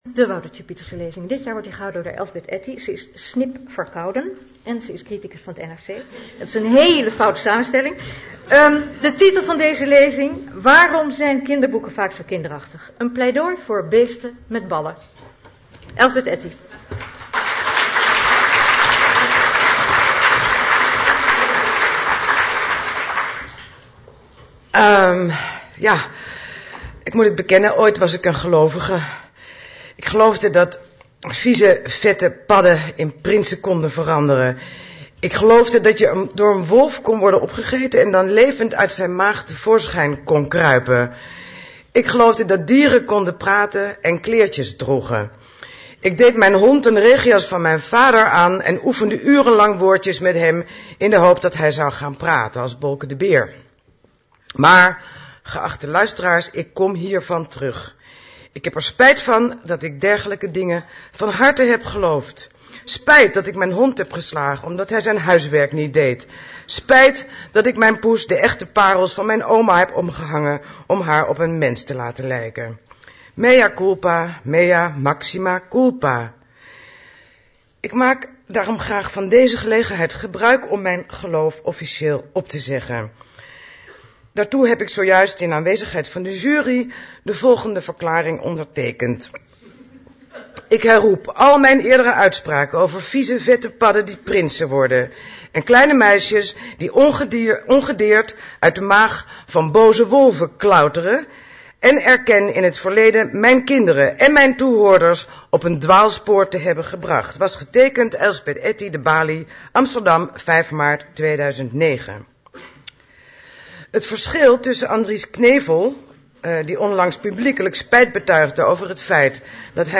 Woutertje Pieterse Lezing 2009